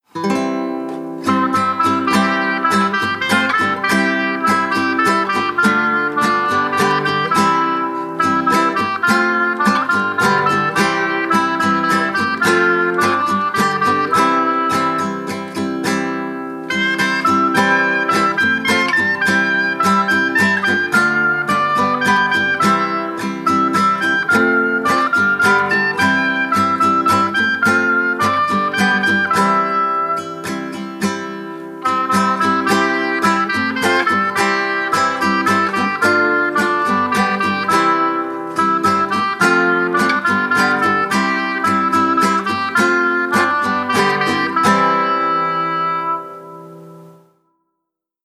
Instrumentalversion